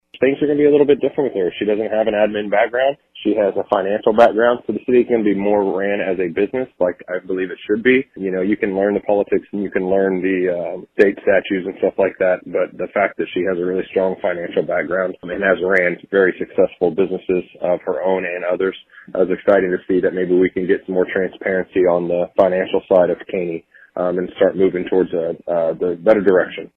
Mayor Josh Elliott says